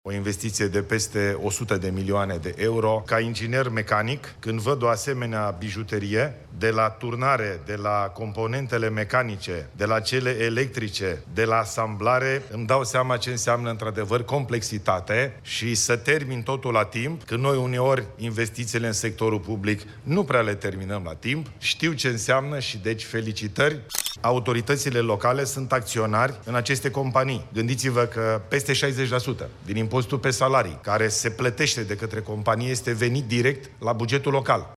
Ilie bolojan a mers într-o fabrică din Sebeș, județul Alba, în care se produc componente pentru mașini electrice (mașinile electrice ale Mercedes-Benz), după ce aceasta a trecut printr-un proces de re-tehnologizare.